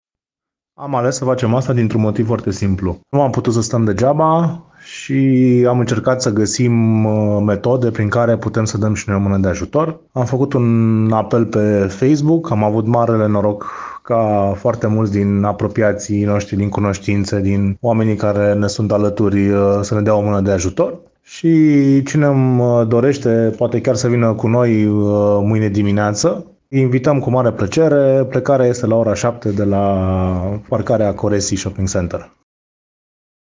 Ei le-au spus colegilor de la Radio Brașov că nu au putut să stea deoparte și și-au dorit să vină în ajutorul refugiaților ucraineni: